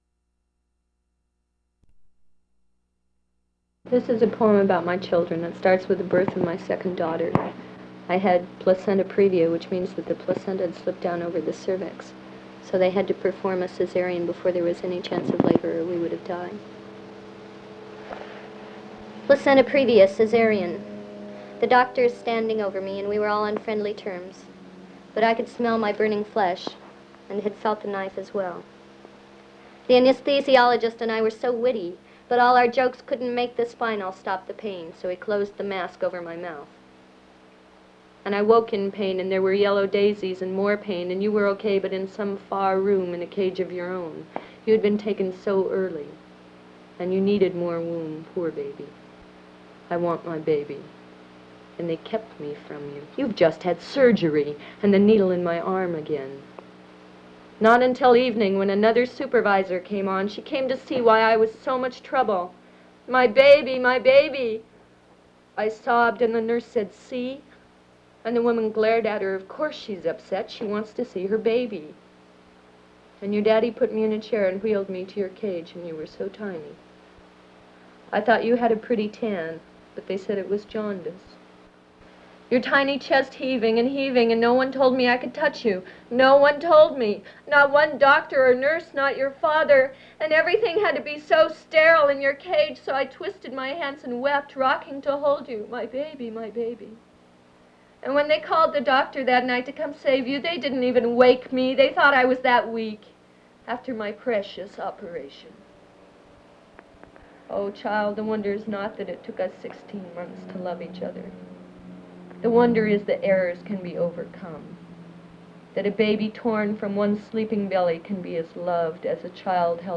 an unknown woman reading a poem written about the birth of her child and motherhood.
1/4 inch audio tape